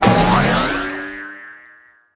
brawl_volley_hit_01.ogg